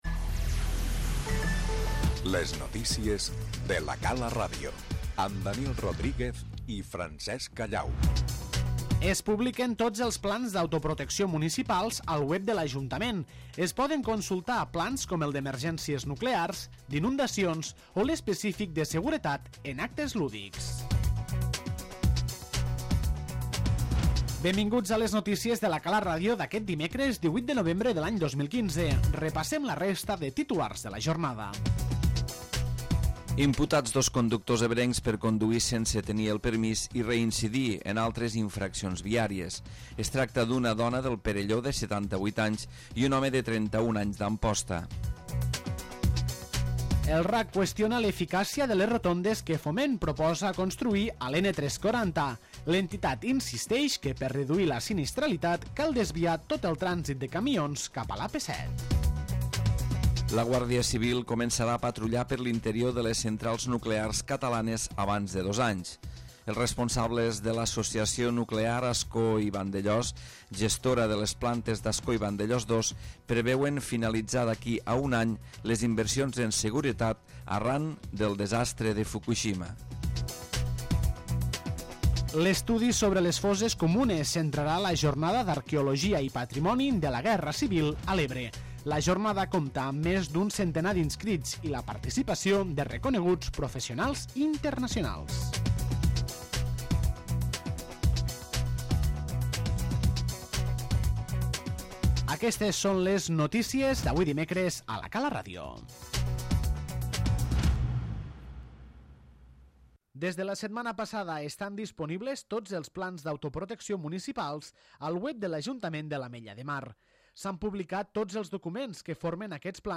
L'informatiu d'aquest dimarts l'obre la publicació dels plans d'autoprotecció municipal a la pàgina web de l'Ajuntament de l'Ametlla de Mar.